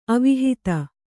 ♪ avihita